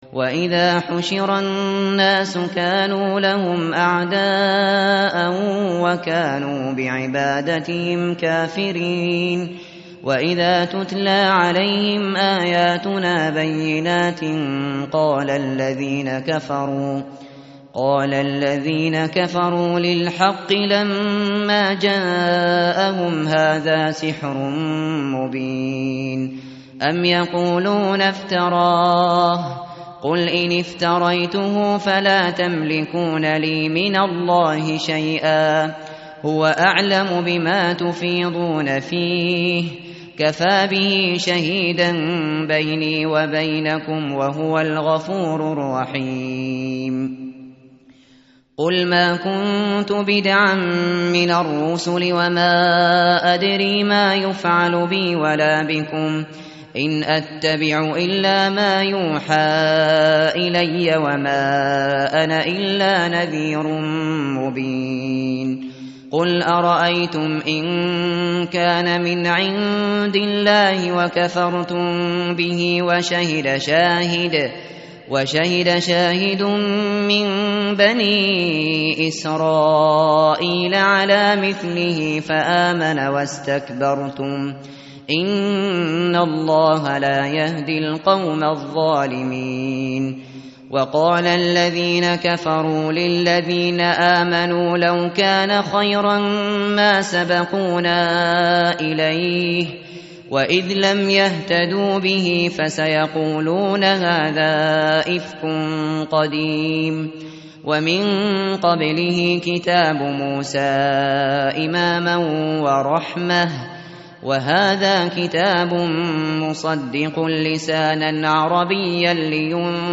متن قرآن همراه باتلاوت قرآن و ترجمه